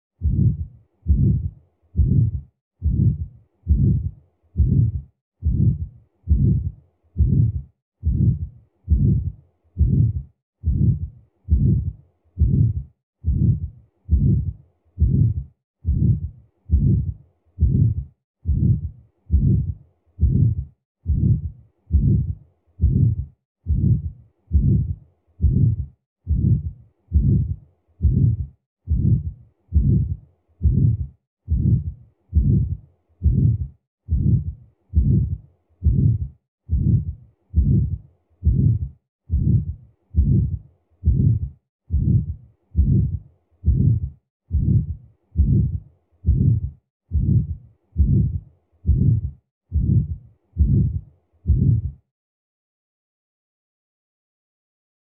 Aortic Stenosis